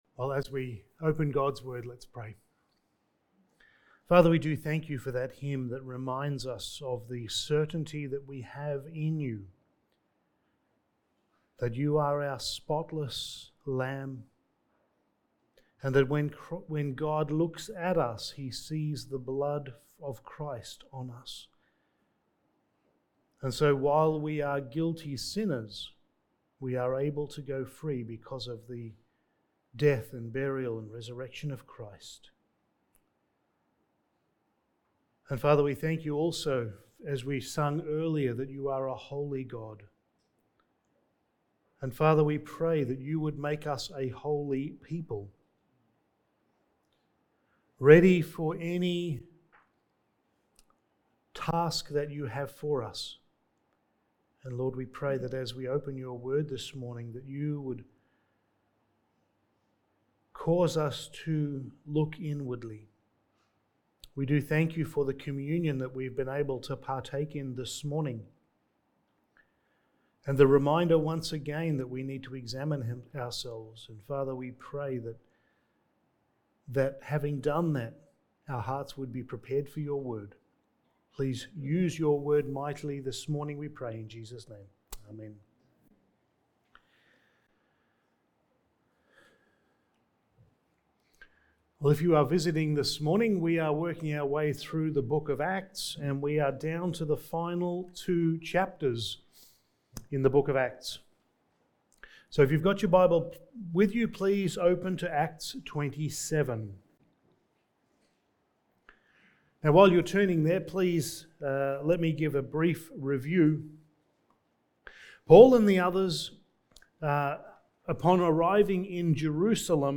Passage: Acts 27:1-26 Service Type: Sunday Morning